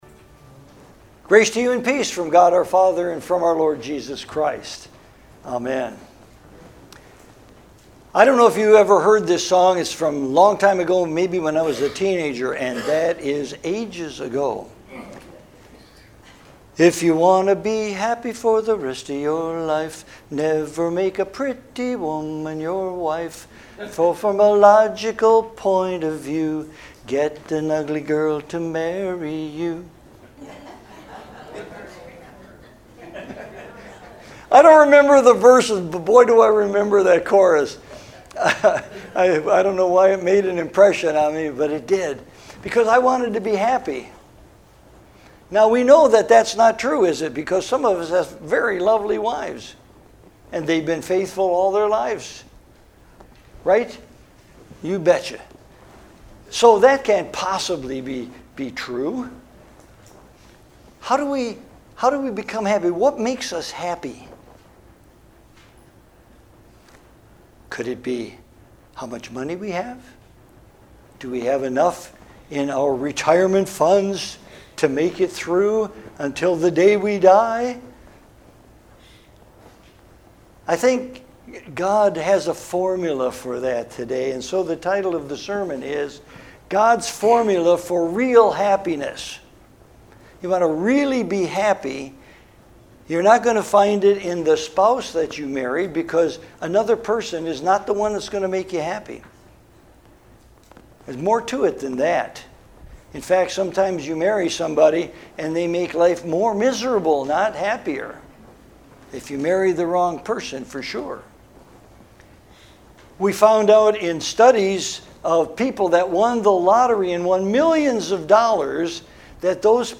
Audio only sermon.